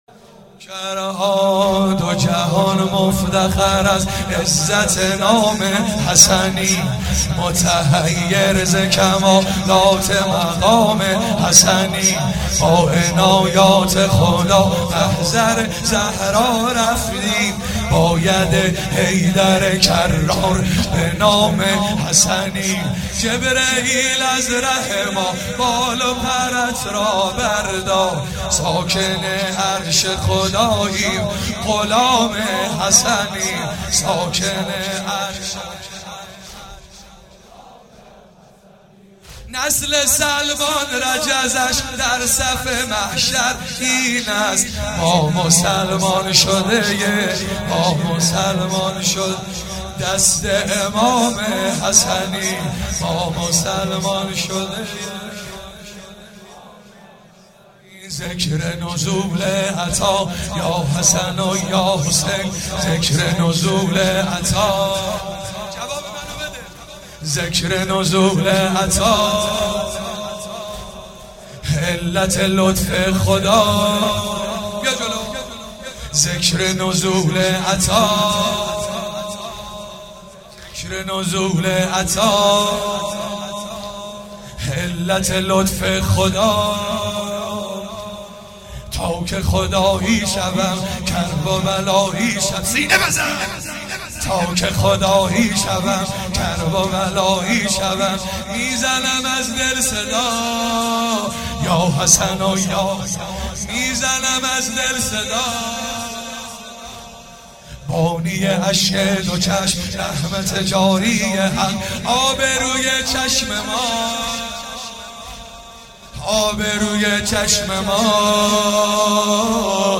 روضه
07 marasem 7 safar94 heiate alamdar mashhad alreza.mp3